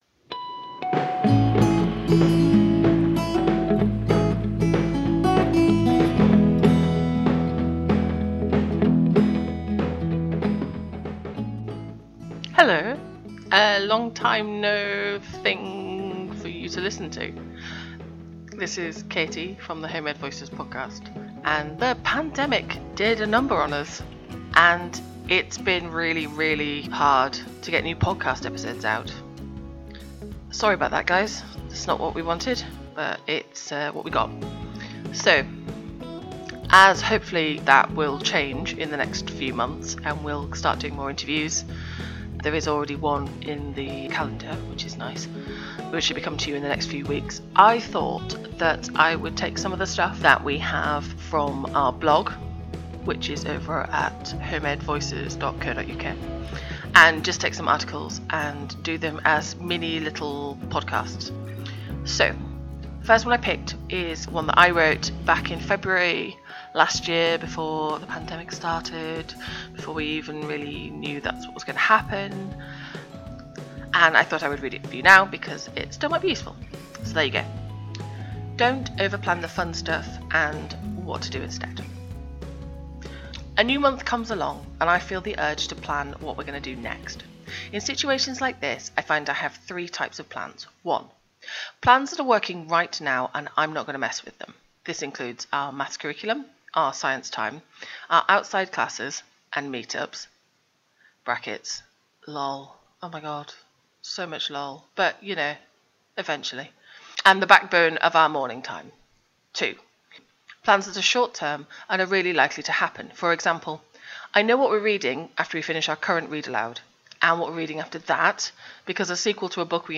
This week is an audio version of the blog post “Don’t Over Plan the Fun Stuff (and What to do Instead)” which was published here on HomeEdVoices in February 2020.